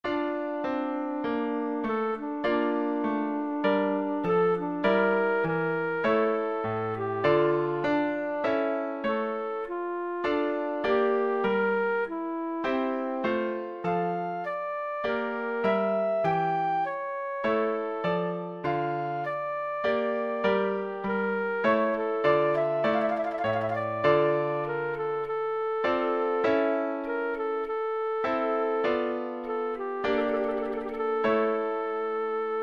Přednesová skladba pro zobcovou flétnu